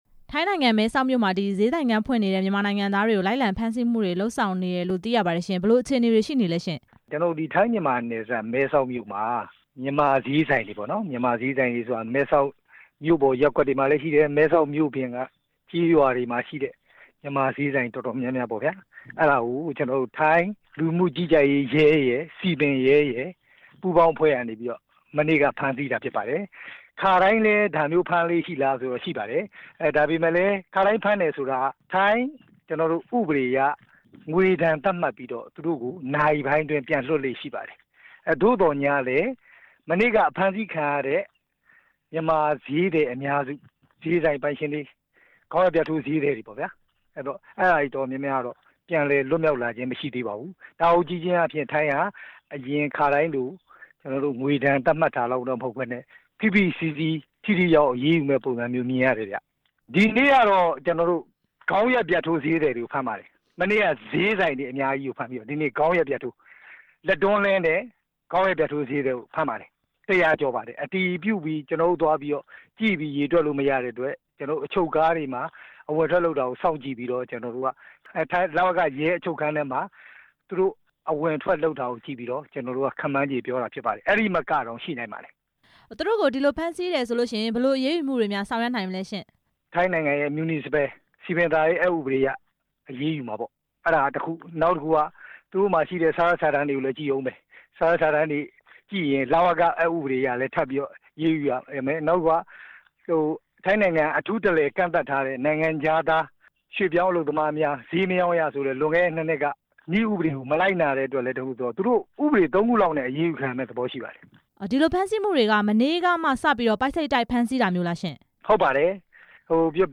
မဲဆောက်က မြန်မာဈေးသည်တွေ အခြေအနေ မေးမြန်းချက်